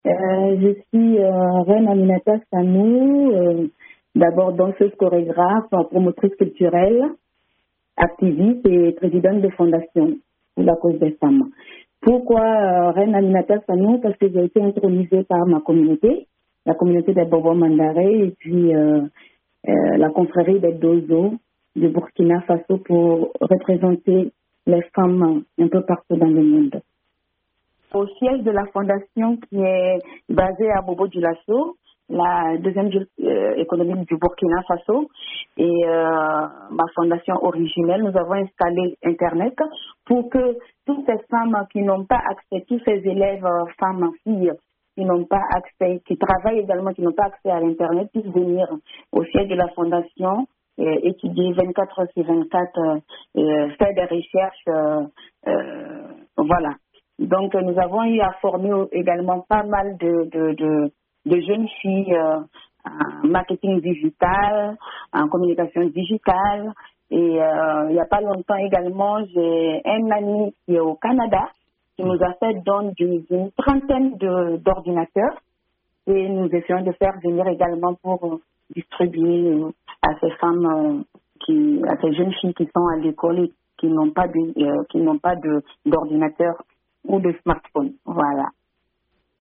À Bobo-Dioulasso, la fondation Origin'ELLES a offert une formation et un accès gratuit à l'internet à des jeunes femmes à l'occasion de la Journée internationale de la femme. Entretien